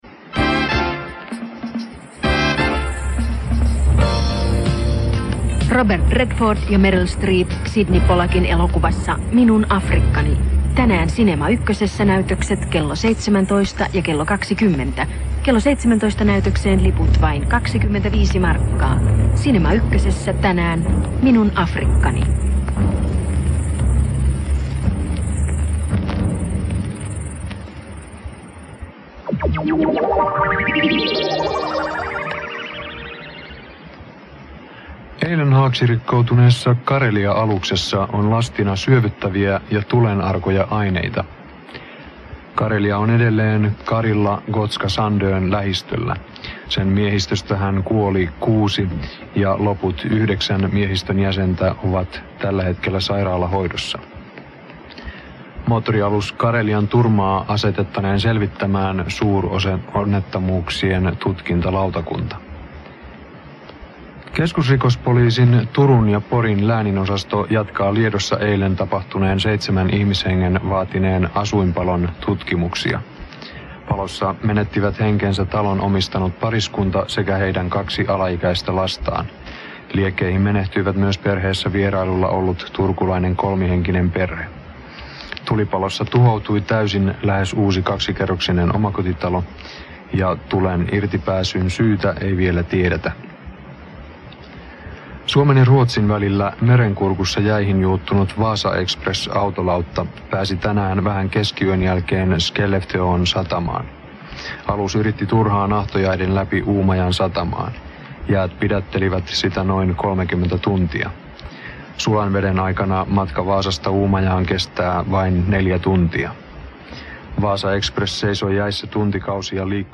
SBC Radion mainos ja uutiset 1986.
SBC-Radio-mainos-ja-uutiset-1986.mp3